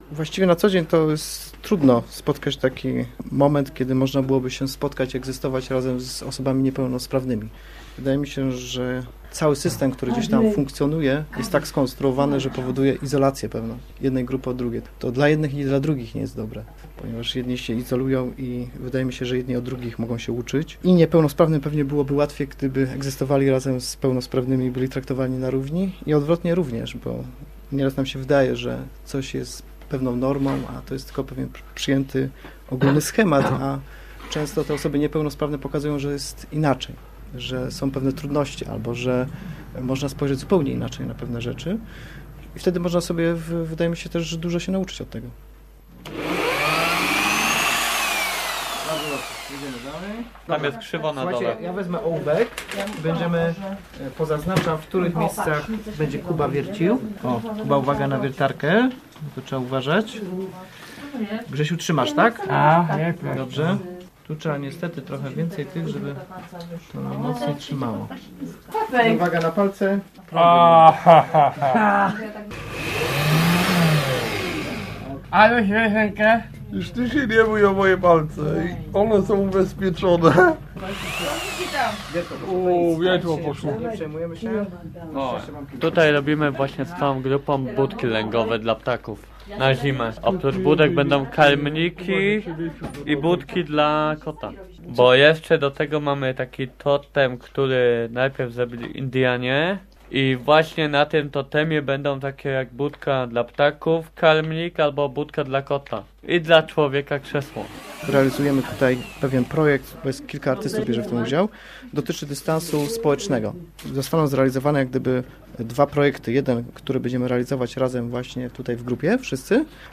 Dystans społeczny - reportaż